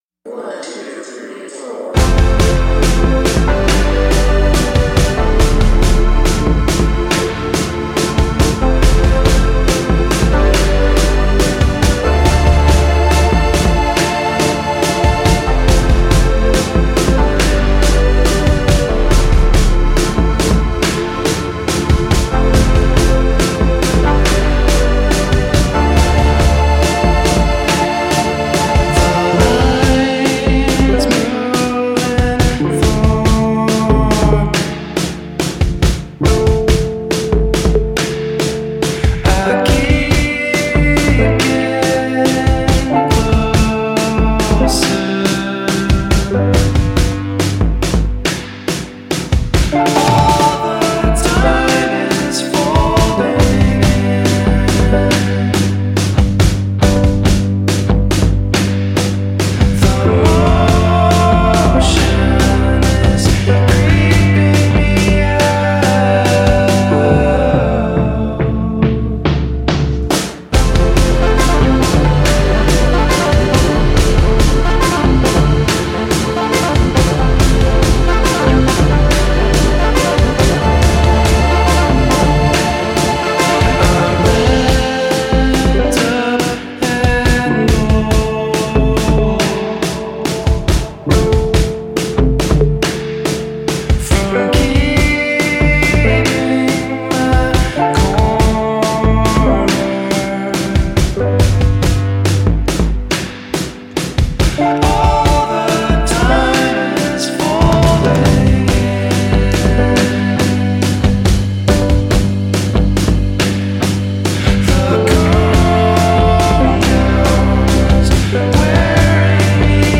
Four-piece Chicago band